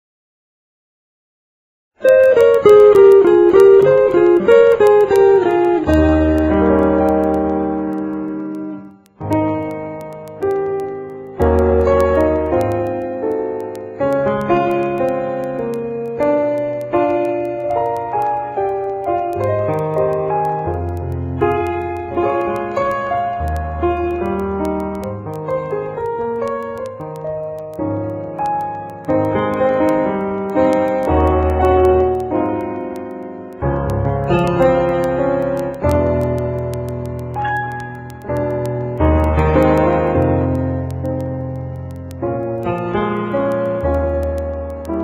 NOTE: Background Tracks 11 Thru 22